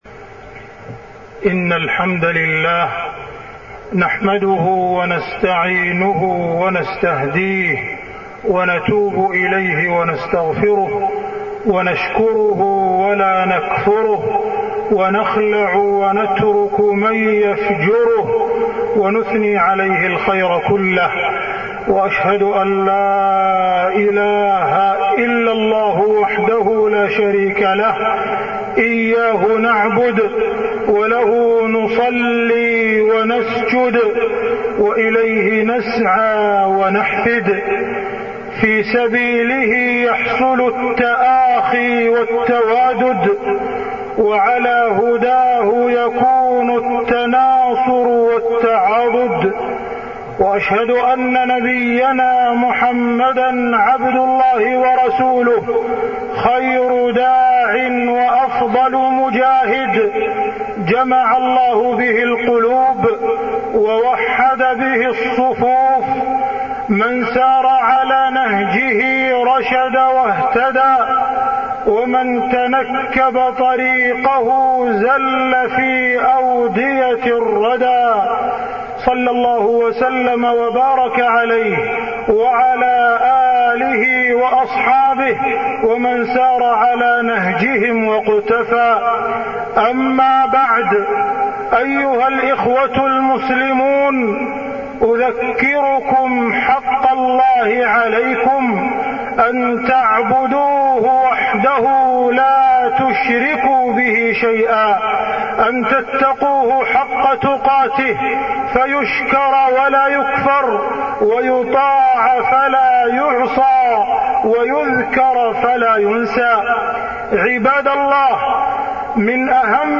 تاريخ النشر ١٣ ذو القعدة ١٤١٢ هـ المكان: المسجد الحرام الشيخ: معالي الشيخ أ.د. عبدالرحمن بن عبدالعزيز السديس معالي الشيخ أ.د. عبدالرحمن بن عبدالعزيز السديس الاتحاد بين المسلمين